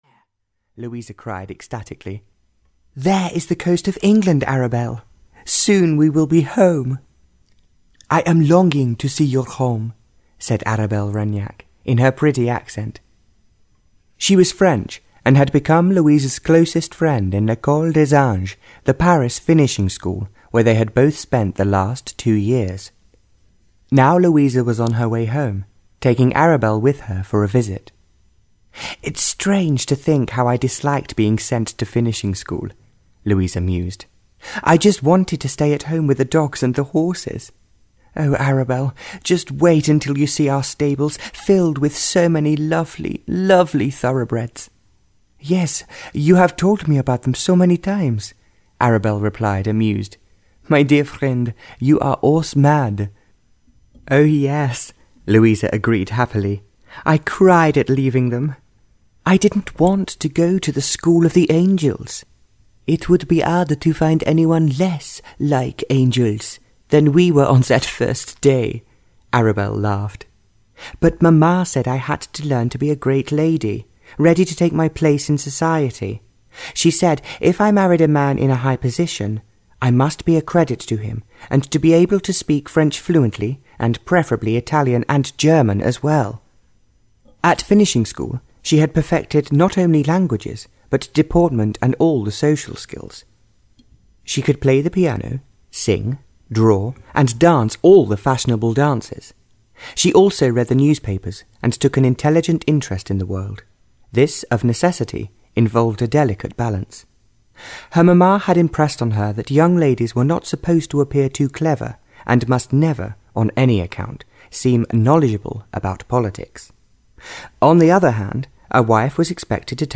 Audio knihaA Heart in Heaven (Barbara Cartland’s Pink Collection 20) (EN)
Ukázka z knihy